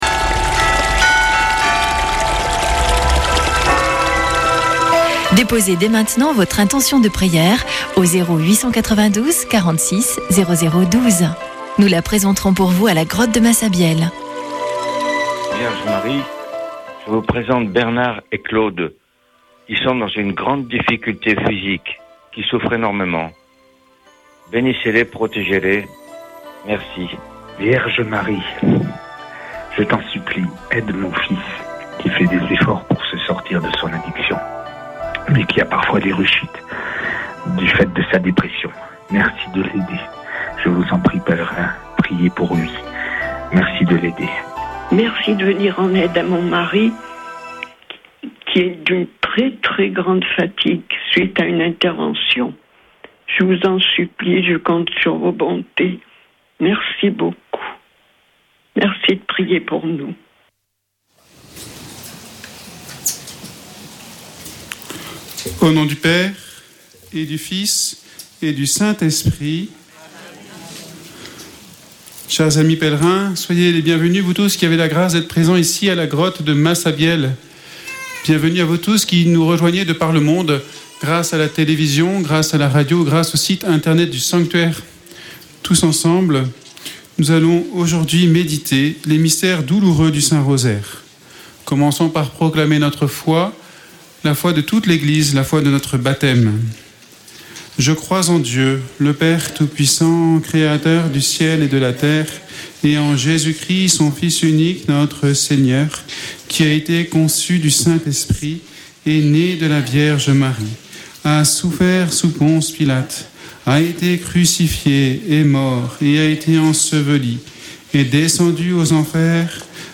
Chapelet de Lourdes du 04 nov.
Une émission présentée par Chapelains de Lourdes